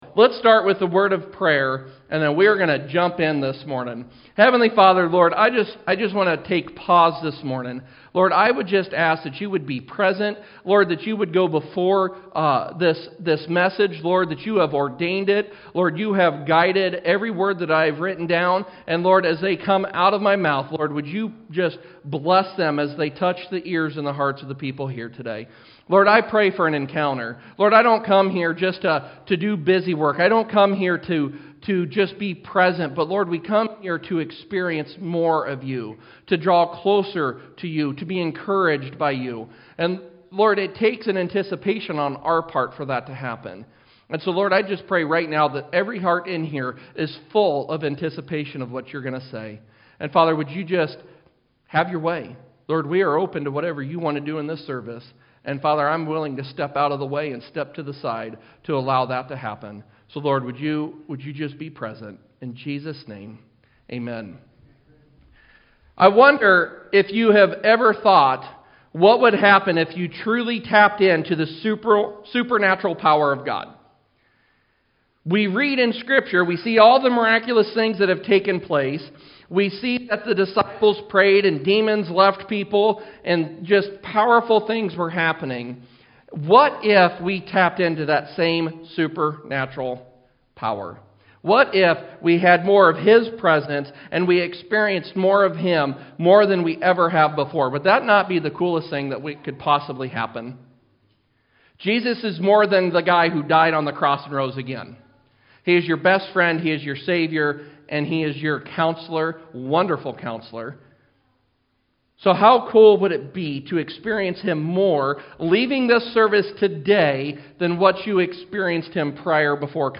Sermons | Engage Church